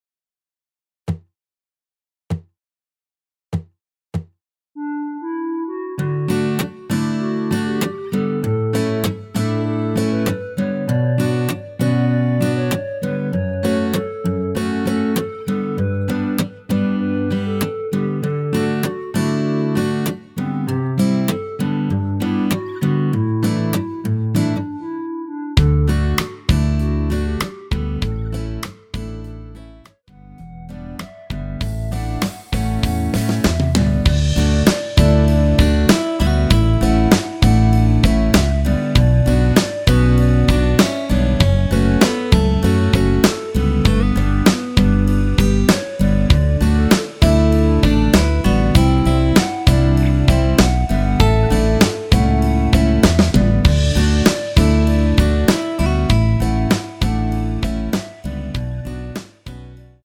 원키 멜로디 포함된 MR이며 노래가 바로 시작 하는 곡이라 전주 만들어 놓았습니다.
멜로디 MR이라고 합니다.
앞부분30초, 뒷부분30초씩 편집해서 올려 드리고 있습니다.
중간에 음이 끈어지고 다시 나오는 이유는